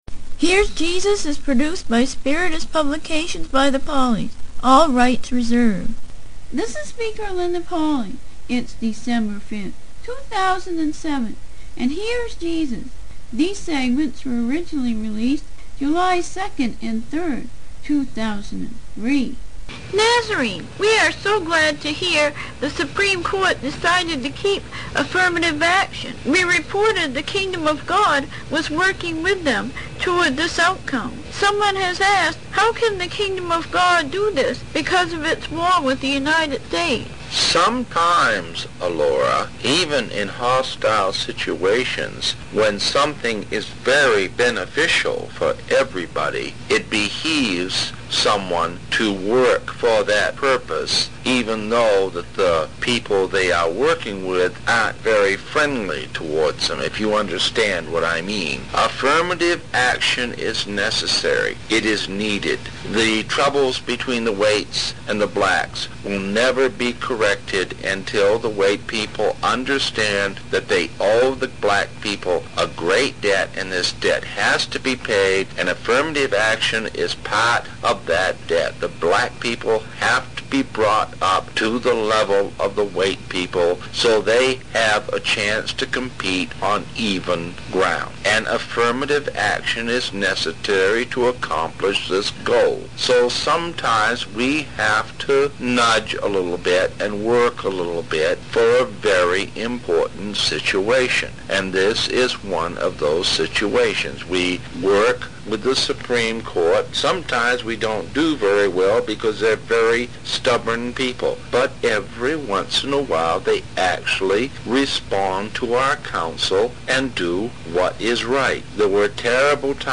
Interviews With
Channeled Through Internationally Known Psychic